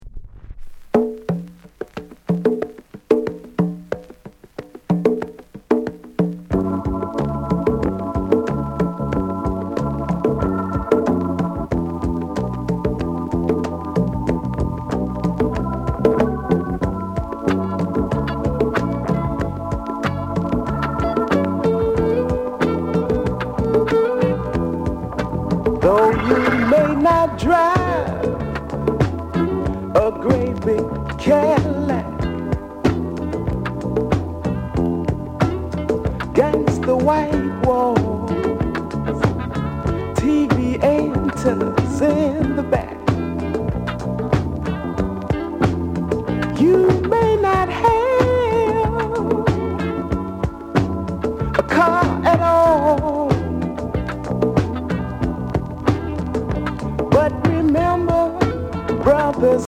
SOUND CONDITION VG
SOUL